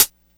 • Nice Hi-Hat Sample A# Key 16.wav
Royality free pedal hi-hat single hit tuned to the A# note. Loudest frequency: 8496Hz
nice-hi-hat-sample-a-sharp-key-16-ve7.wav